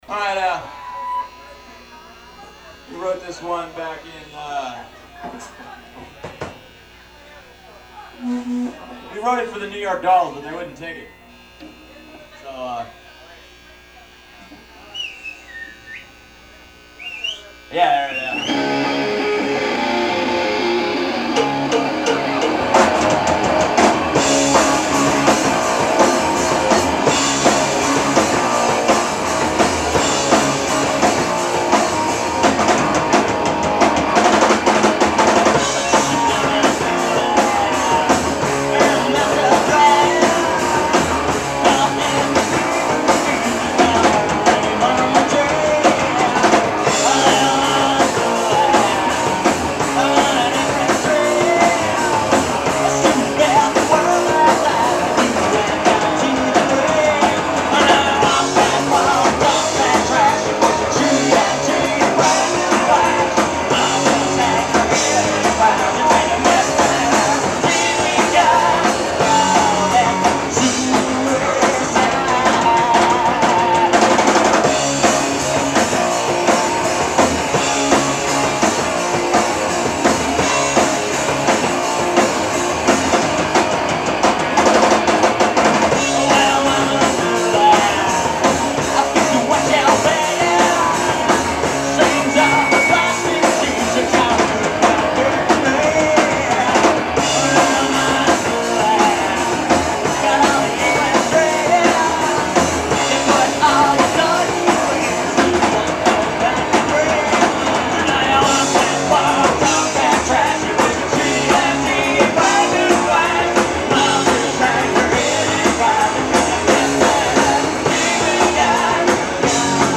Khyber tape
Khyber Pass, Philadelphia 8-12-95